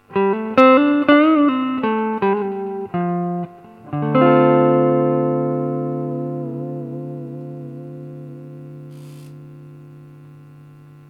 Записано все на советский конденсаторный микрофон (или в линию).
Теперь, собственно комбик:
без эквалайзера и вариант